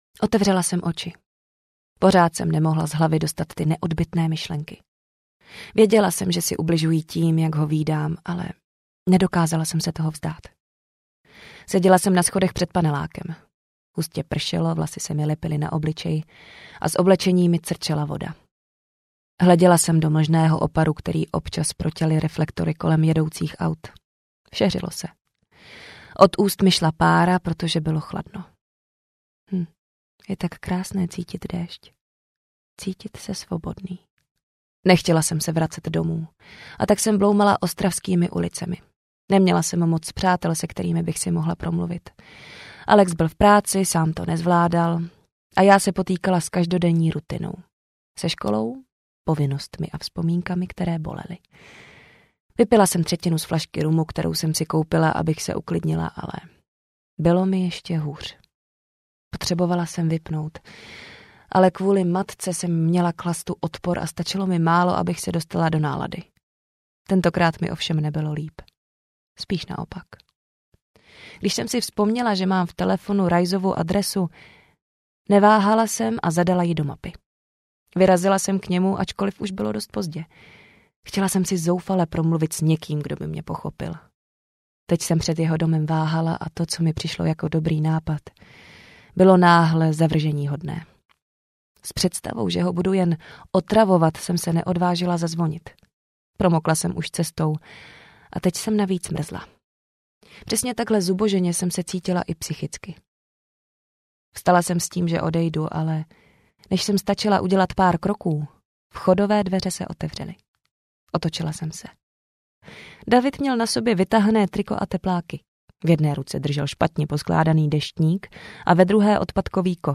Vzpomínky na zítřek audiokniha
Ukázka z knihy